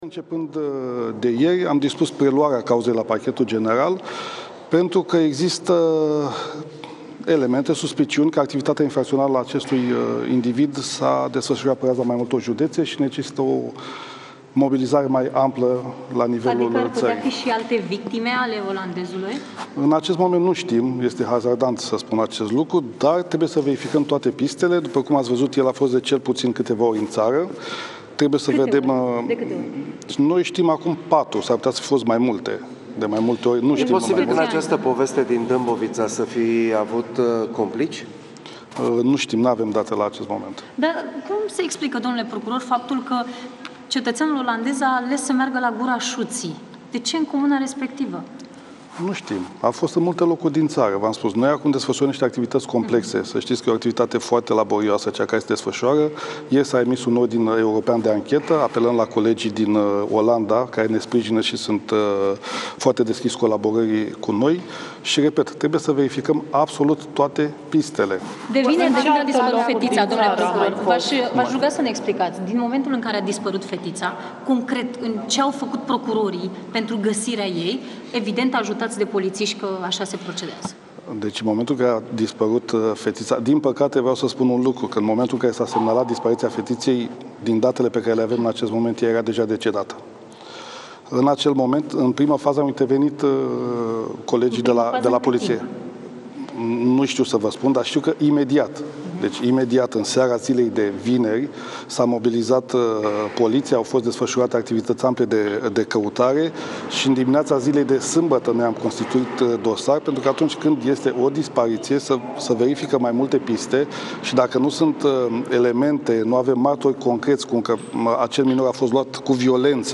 Procurorul general interimar a anunțat, marți dimineață, că sunt verificate “absolut toate pistele” în ancheta uciderii fetiței din Dâmbovița.